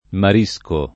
marisco [ mar &S ko ] s. m.; pl. ‑schi